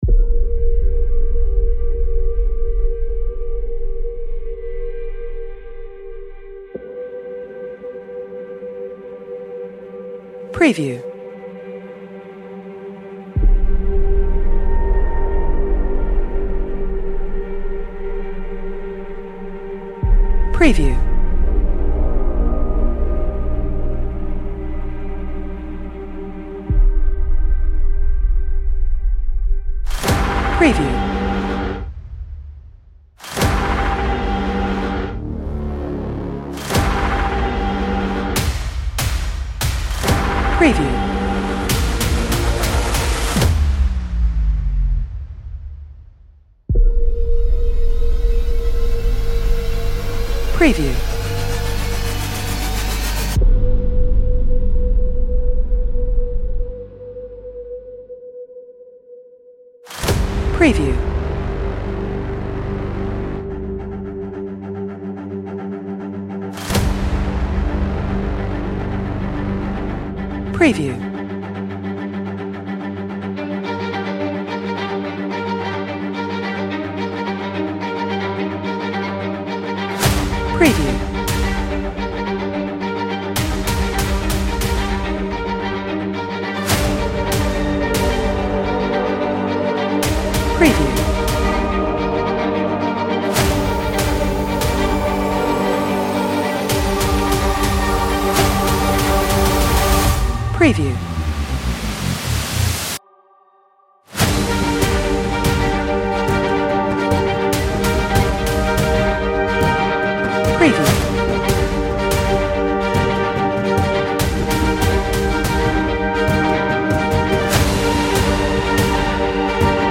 The cues feel composed for unease, not just volume.